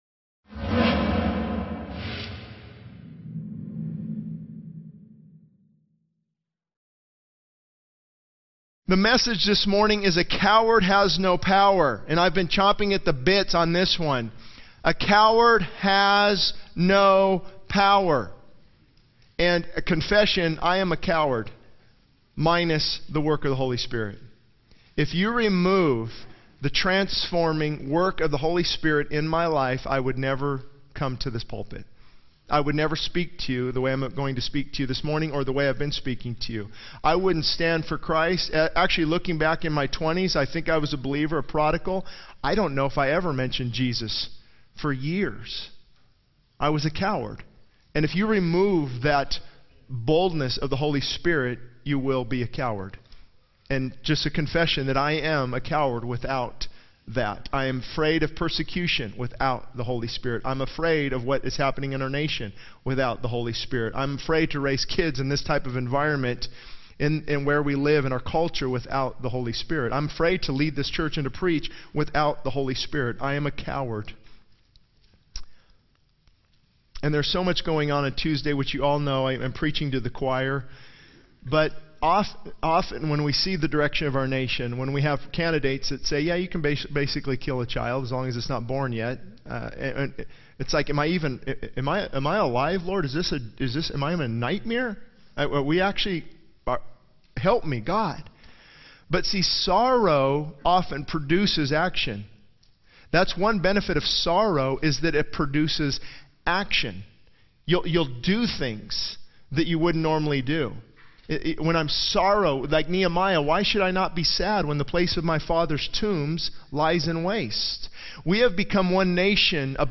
This sermon emphasizes the contrast between being a coward without power and being filled with the Holy Spirit, highlighting the need for boldness, intercession, and agonizing prayer for the nation and the church. It calls for believers to step out in faith, identify with the sins of the nation, and pray with authority and agony, drawing inspiration from biblical figures who stood boldly for God despite challenges.